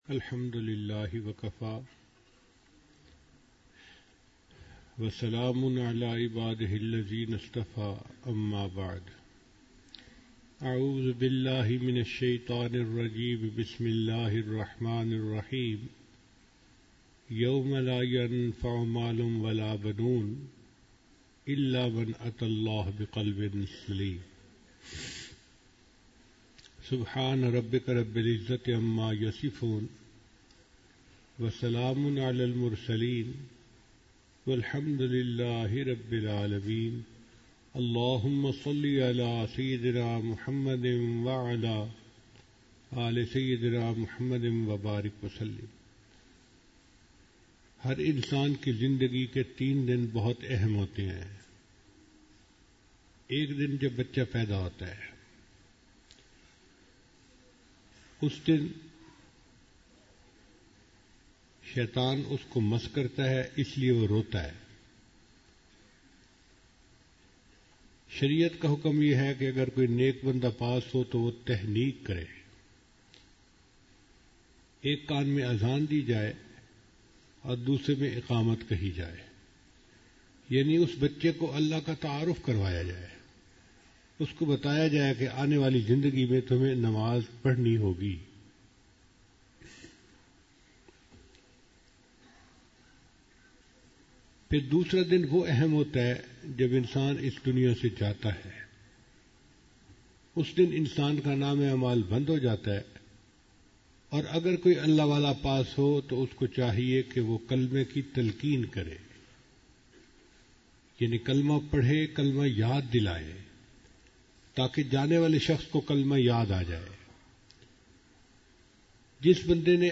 16 Nov 2019 | Mahad-ul-Faqeer, Jhang | 56m 53s